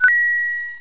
coin.wav